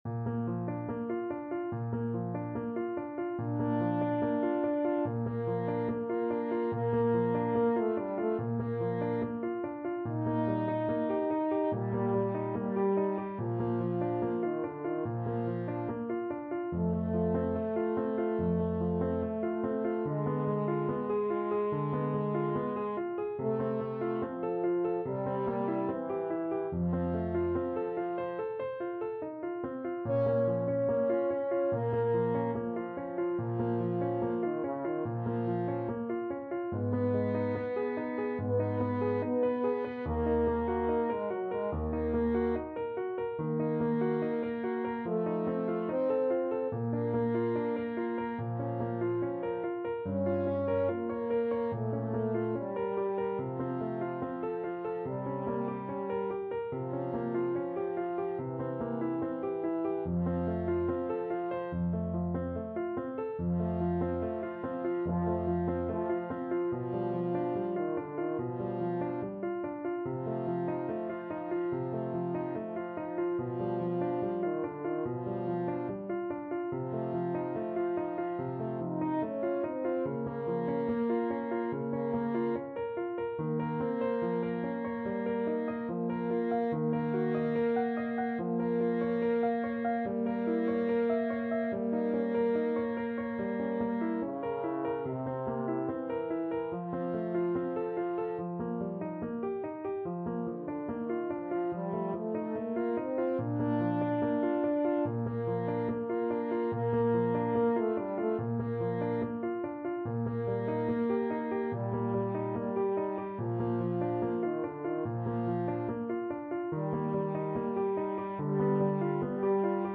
Andante =72
Classical (View more Classical French Horn Duet Music)